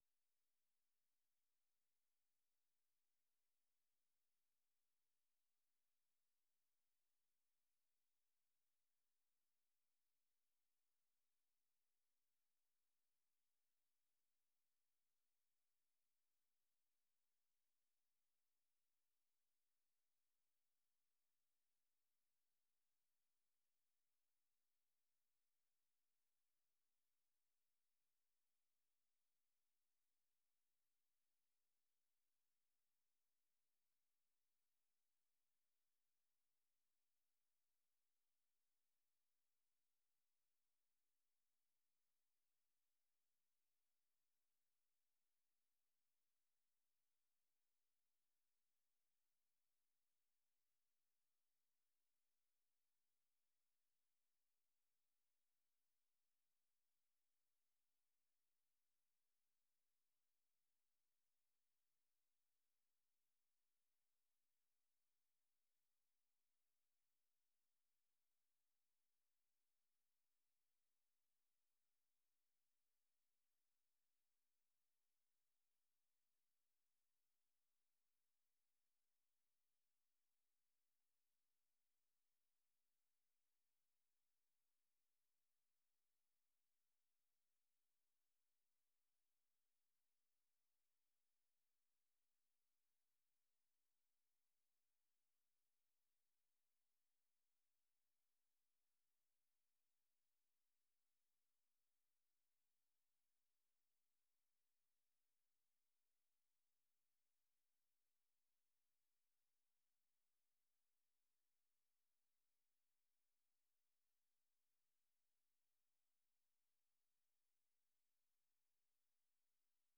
Half-hour broadcasts in Afaan Oromoo of news, interviews with newsmakers, features about culture, health, youth, politics, agriculture, development and sports on Monday through Friday evenings at 8:30 in Ethiopia and Eritrea.